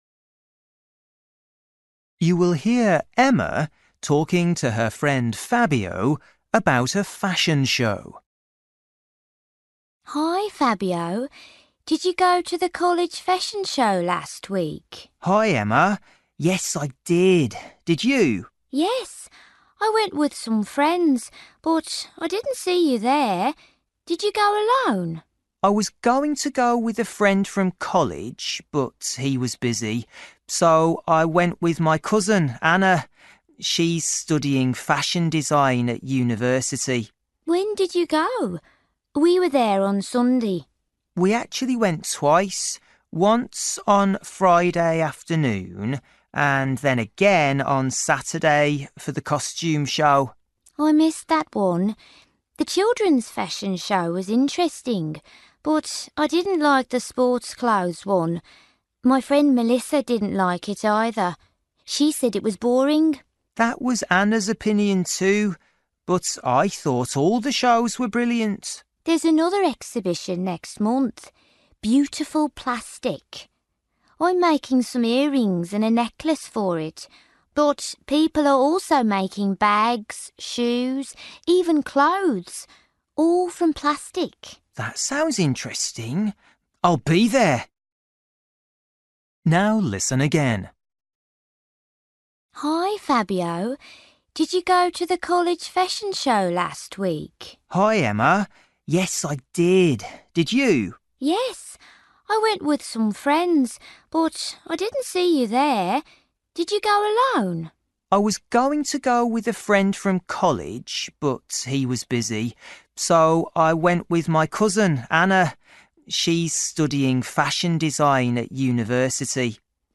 Bài tập trắc nghiệm luyện nghe tiếng Anh trình độ sơ trung cấp – Nghe một cuộc trò chuyện dài phần 10